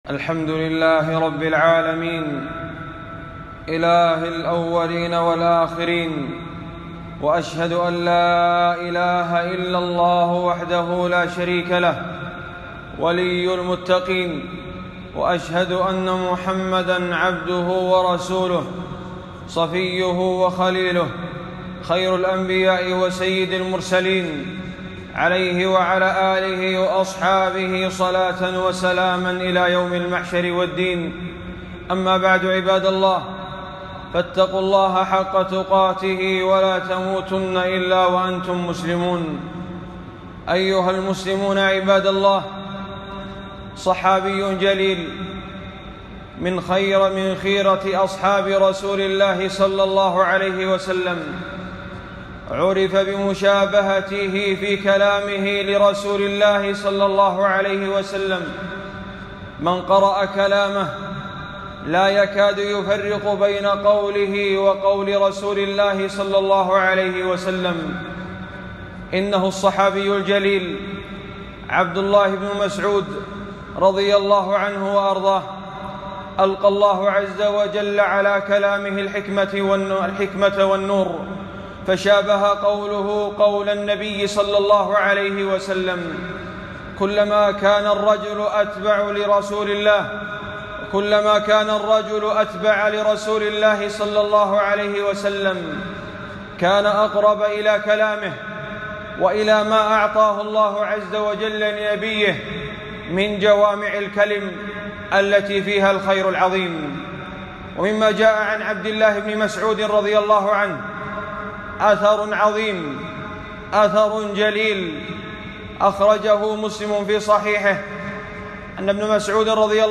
خطبة - من سره أن يلقى الله غدًا مسلمًا